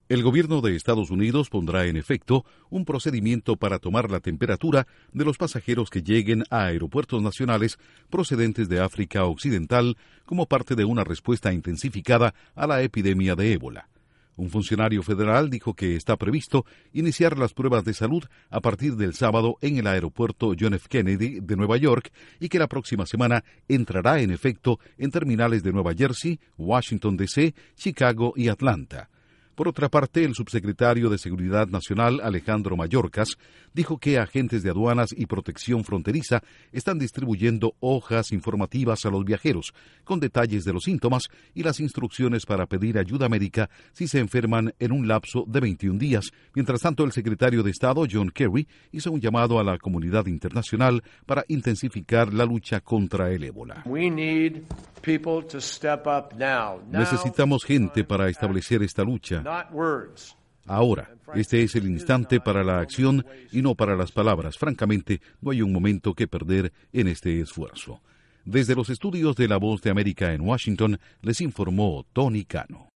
Aeropuertos de Estados toman medidas en vuelos procedentes de África Occidental por el virus del ébola. Informa desde la Voz de América en Washington